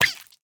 Minecraft Version Minecraft Version snapshot Latest Release | Latest Snapshot snapshot / assets / minecraft / sounds / mob / axolotl / hurt4.ogg Compare With Compare With Latest Release | Latest Snapshot
hurt4.ogg